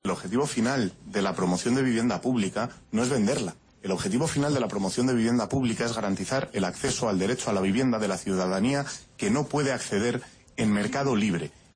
Ramón Espinar en 2015 en una Comisión de la Asamblea